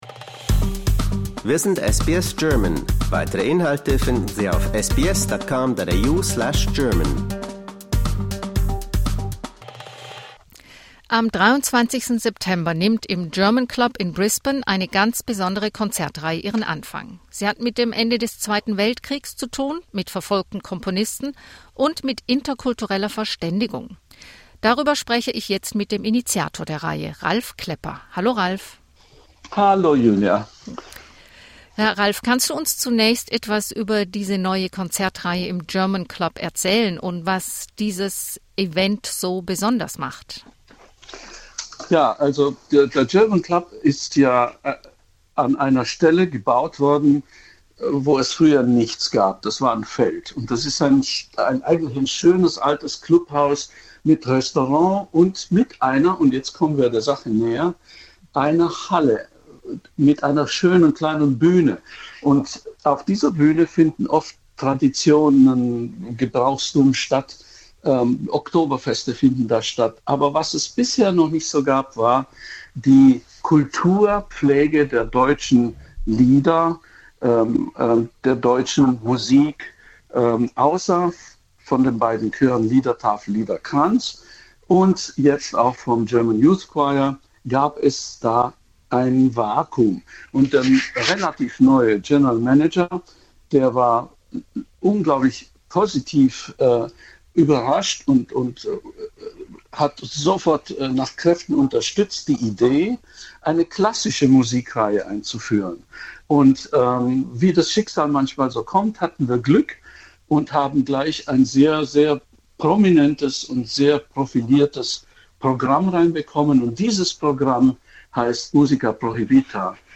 For more stories, interviews and news from SBS German, explore our podcast collection here .